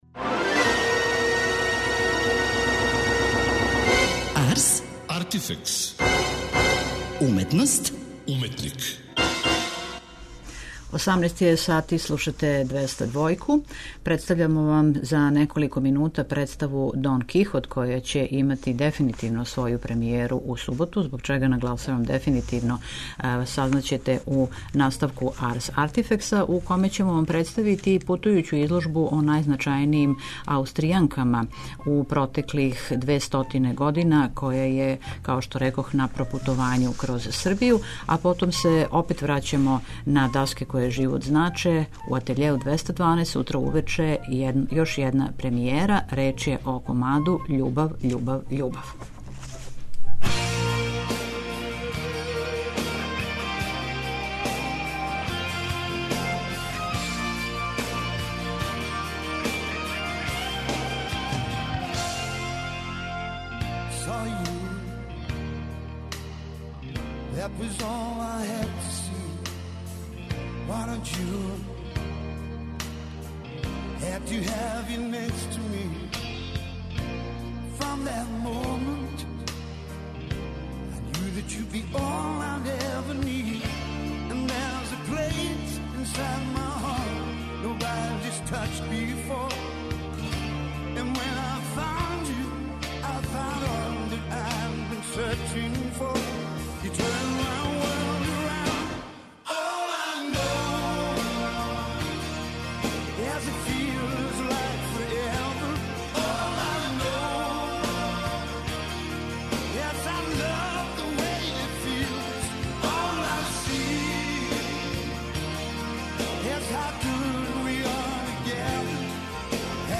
преузми : 27.30 MB Ars, Artifex Autor: Београд 202 Ars, artifex најављује, прати, коментарише ars/уметност и artifex/уметника. Брзо, кратко, критички - да будете у току.